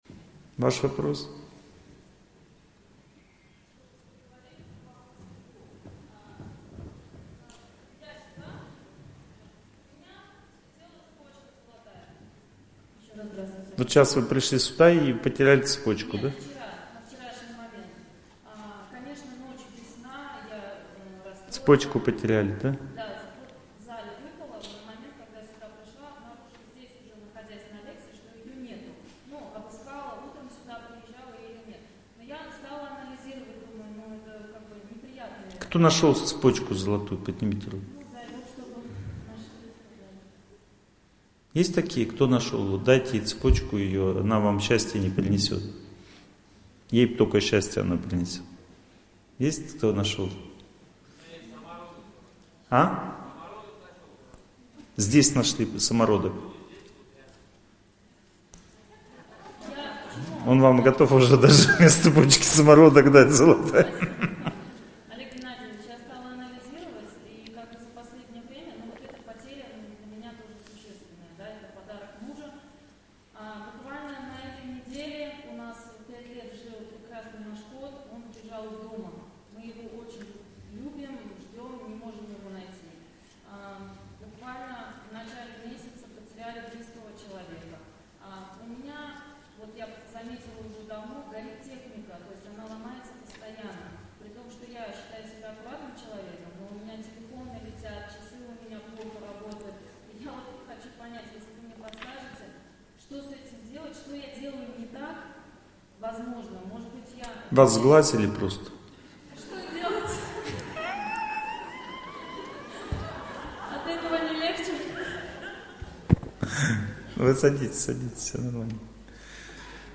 Uspeshnaya-zhizn-v-bolshom-gorode-Lekciya-3.mp3